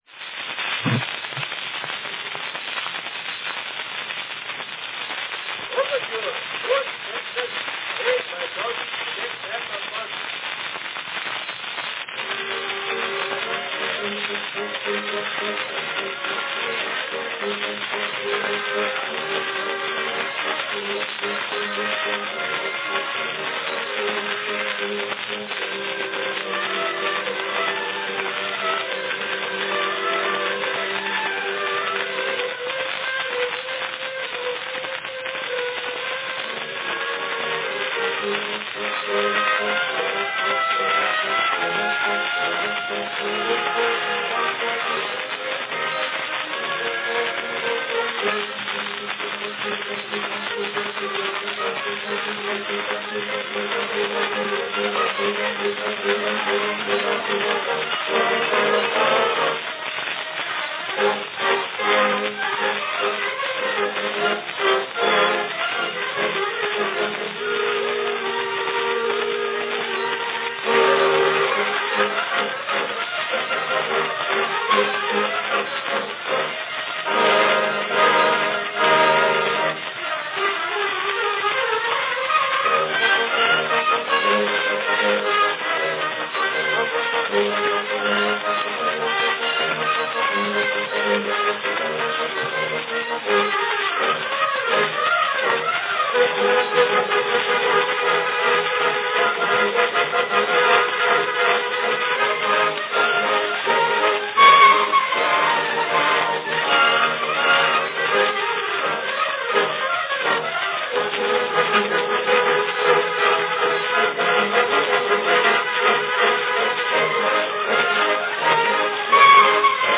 Enjoy a rousing recording from 1895 of Overture, Poet and Peasant performed by Baldwin's Cadet Band of Boston.
Category Band
Performed by Baldwin's Cadet Band
Announcement "Overture, Poet and Peasant, played by Baldwin's Cadet Band of Boston."
Notice the string section: fairly uncommon in early sound recordings, particularly in "band" records.